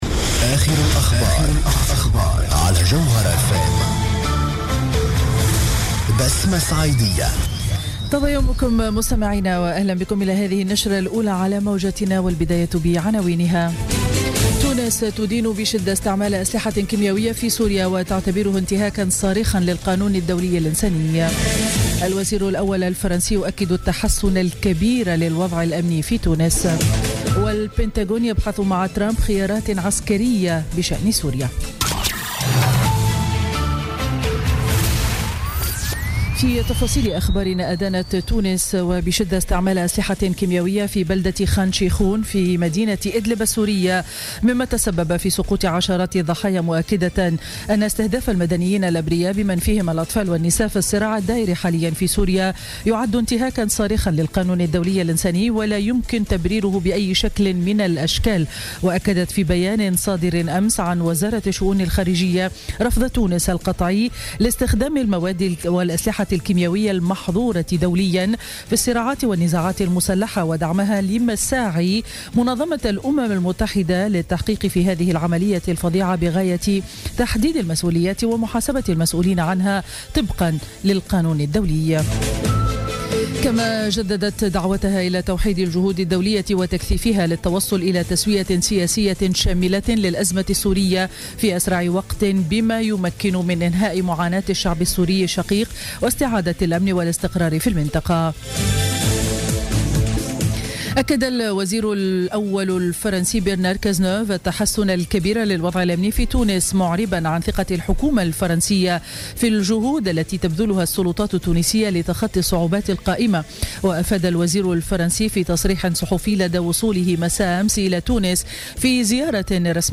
نشرة أخبار السابعة صباحا ليوم الجمعة 7 أفريل 2017